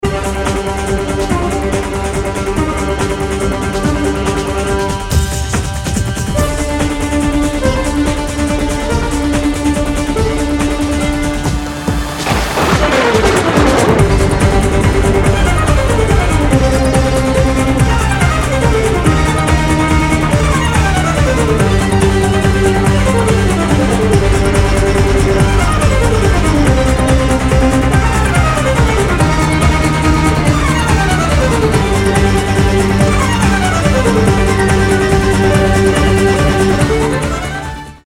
красивые
без слов
скрипка
инструментальные
techno
классика